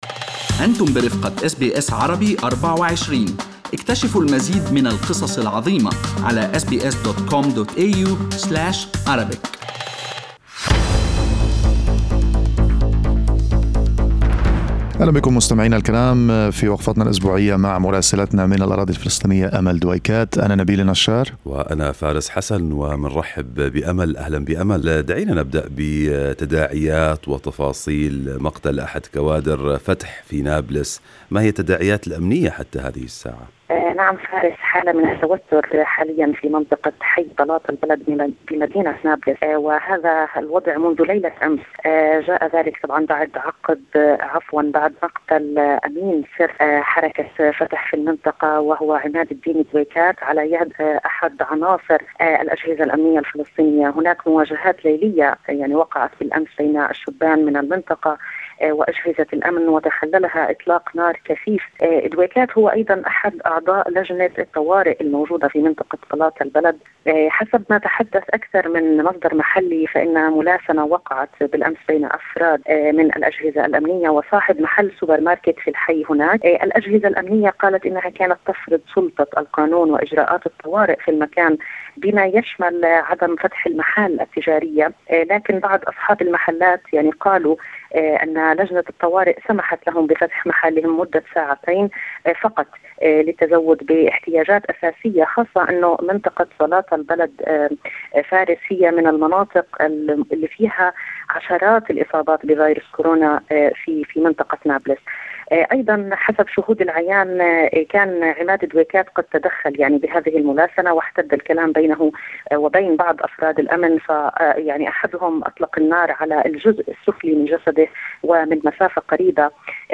يمكنكم الاستماع إلى تقرير مراسلنا في الأراضي الفلسطينية بالضغط على التسجيل الصوتي أعلاه.